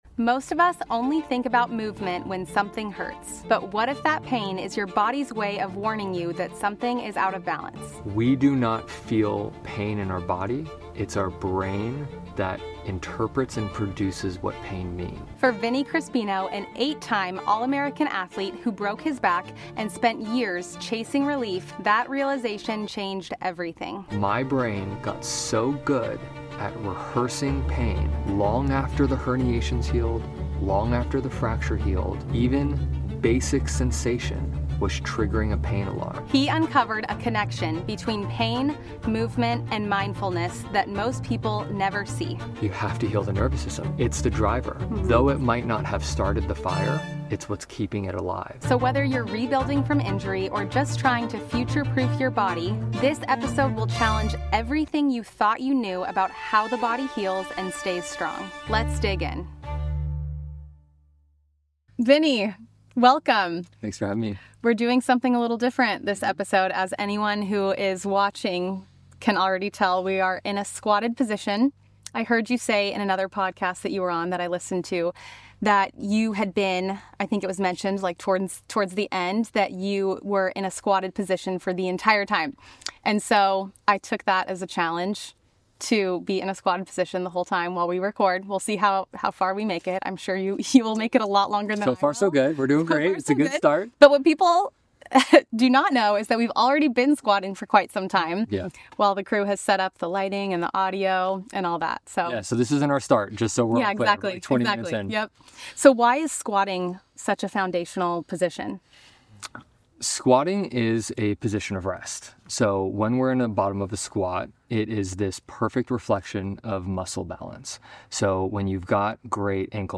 This conversation is equal parts practical and deeply human.